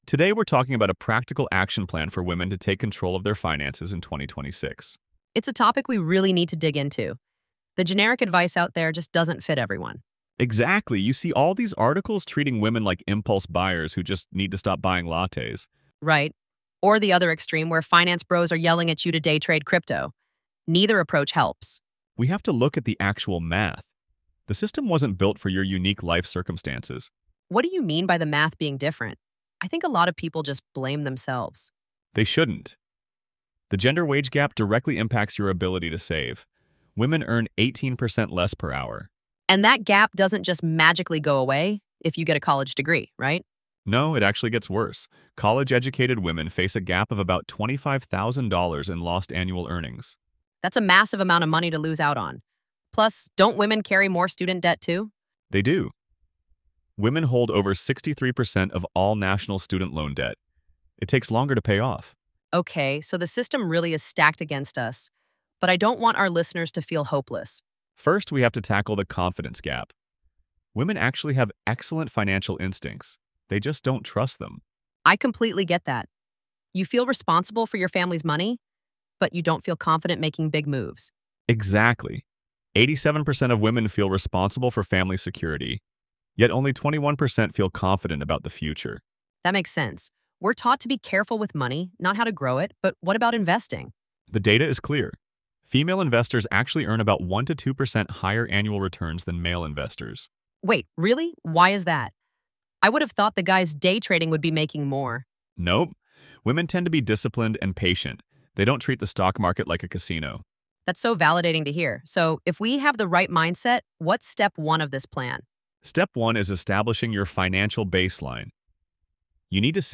AI-generated audio · Voices by ElevenLabs